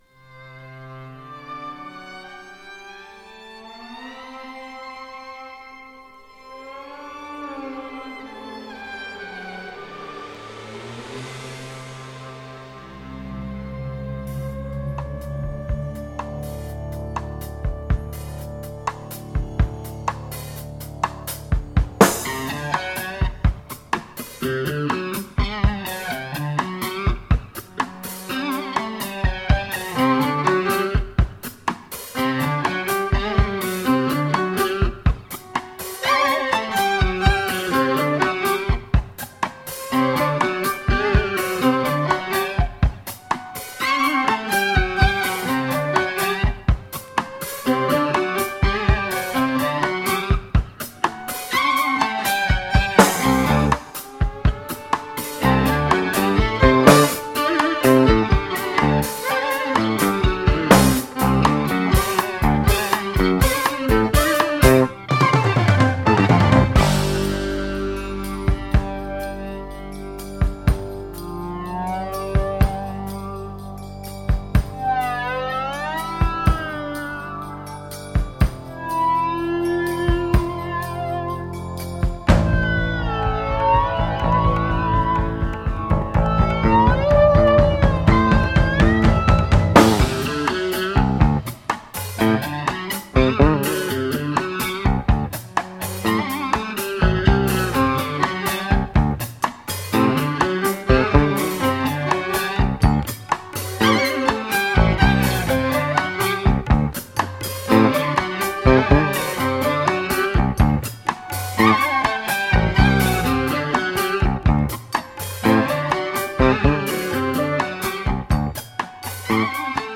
BALEARIC〜ORIENTAL JAZZ !!